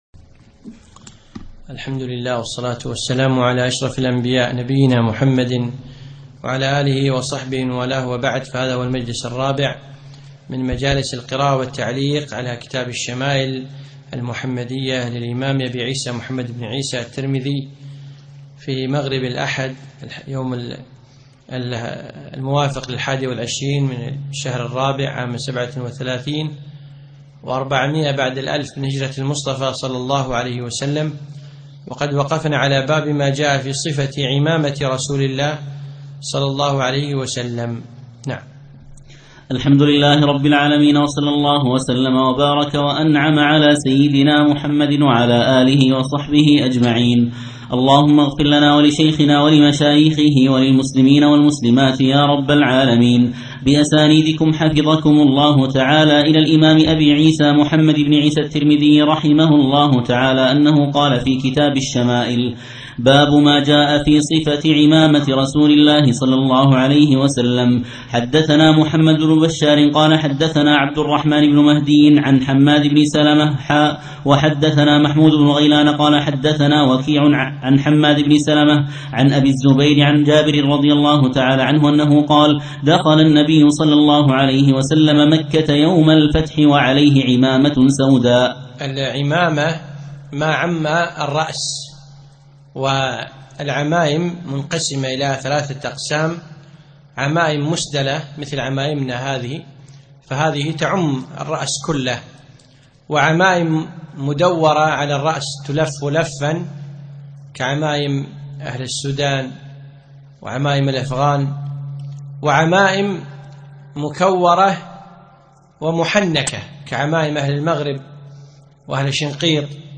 يوم الأحد 22 ربيع الأخر 1437هـ الموافق 1 2 2016م في مسجد عائشة المحري المسايل